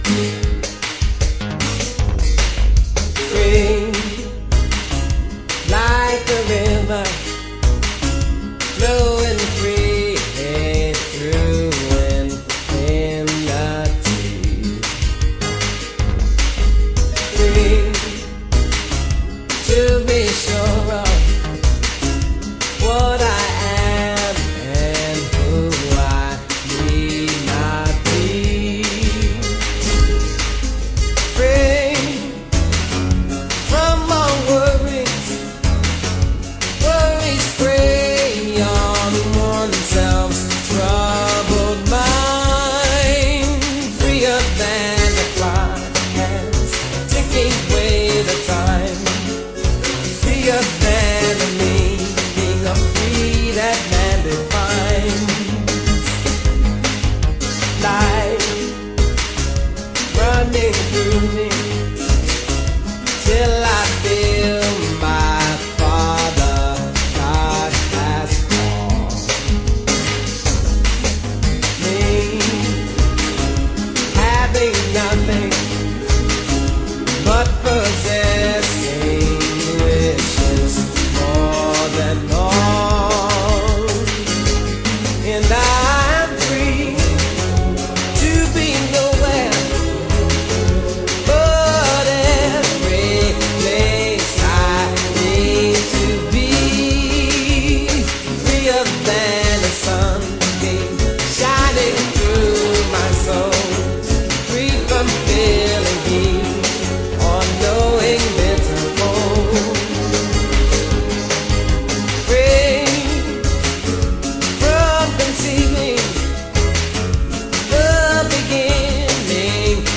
Avec le Midi de la partition : j’ai craqué à 1:13 (1/3 …)